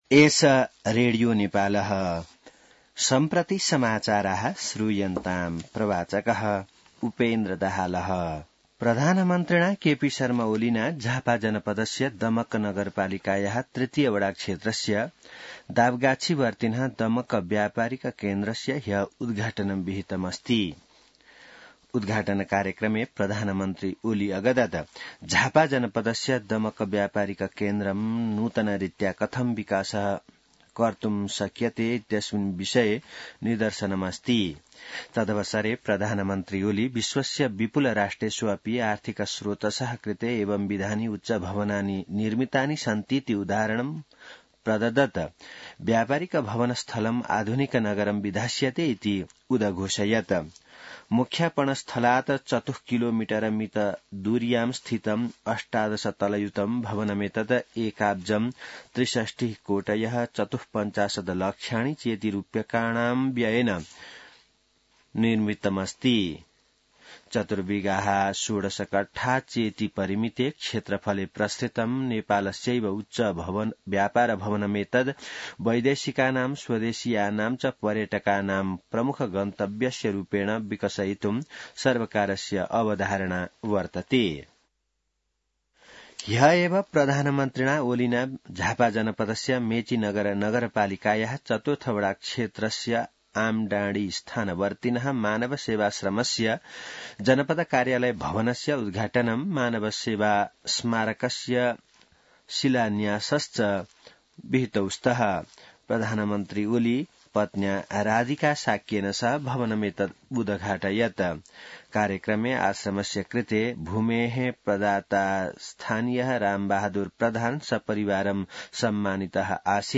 संस्कृत समाचार : २५ मंसिर , २०८१